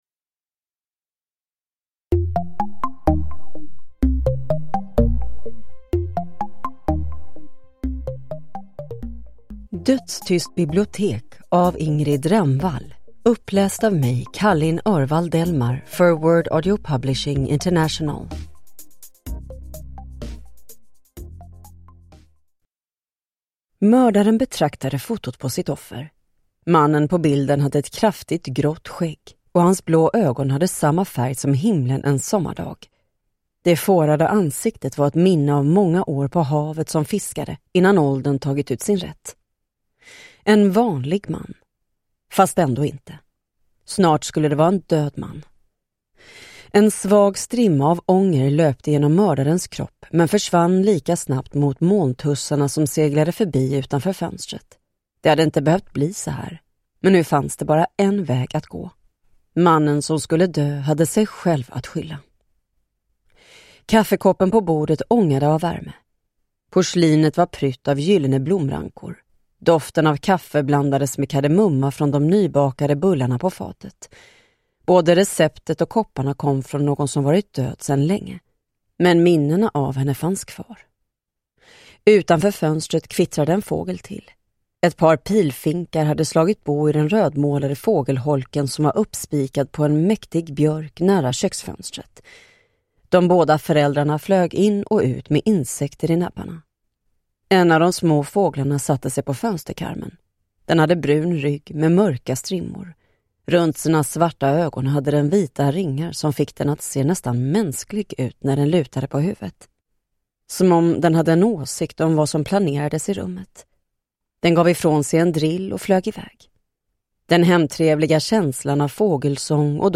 Dödstyst bibliotek – Ljudbok